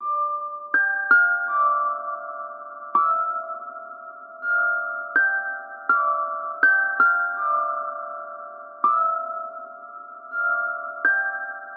Tag: 163 bpm Trap Loops Bells Loops 1.98 MB wav Key : D